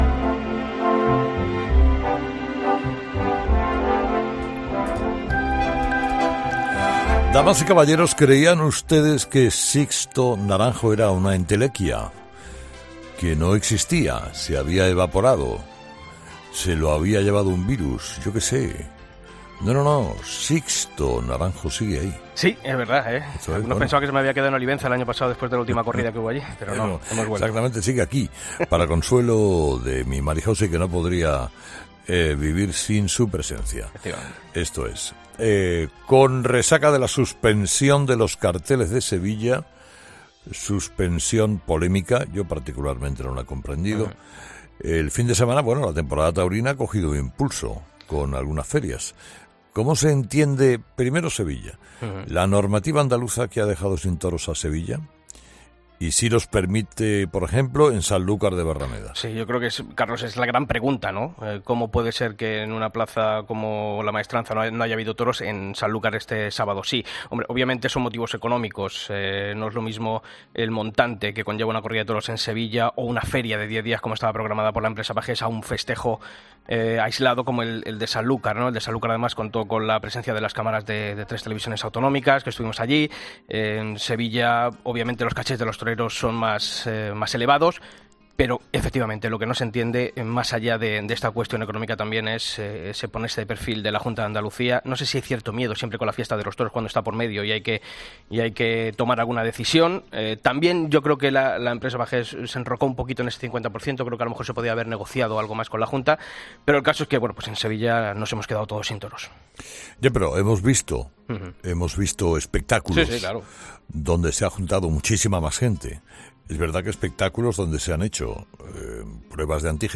El diestro sevillano ha pasado hoy por Herrera en COPE tras no haber podido torear este domingo en la Real Maestranza de Sevilla.